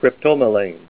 Help on Name Pronunciation: Name Pronunciation: Cryptomelane + Pronunciation
Say CRYPTOMELANE Help on Synonym: Synonym: Psilomelane